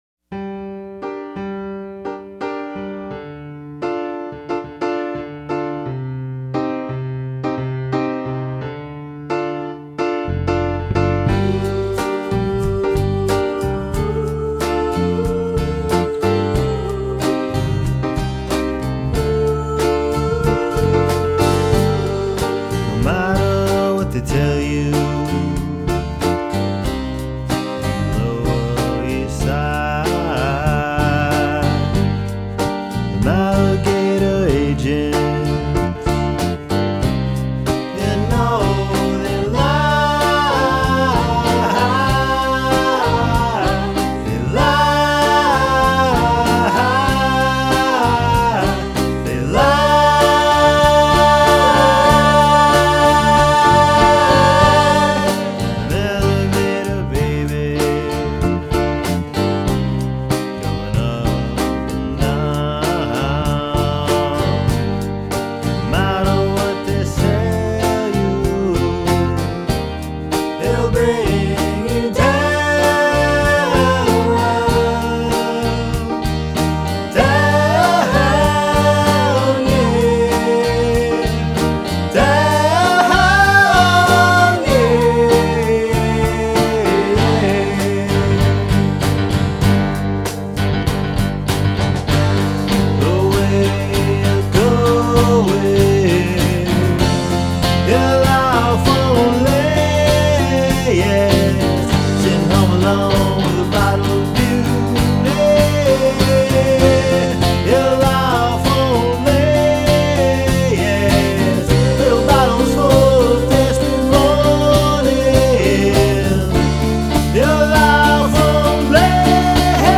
boy-girl piano/guitar avant-garde sing along music